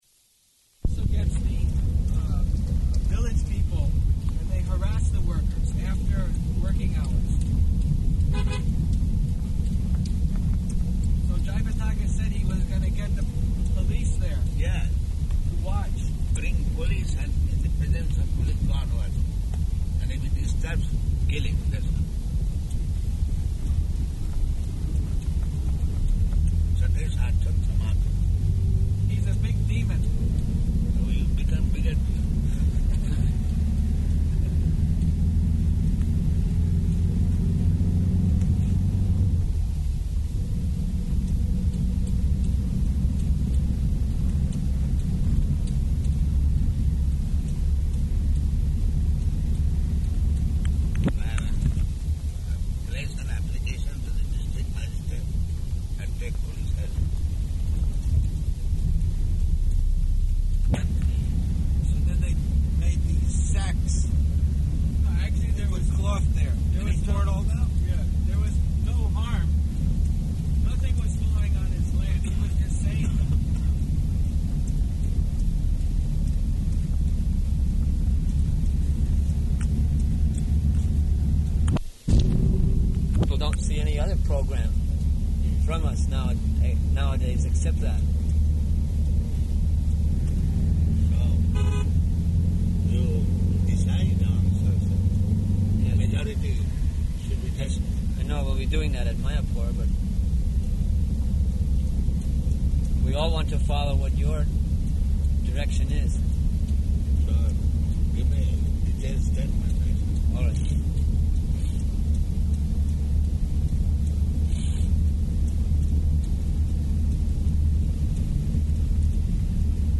Conversation During Massage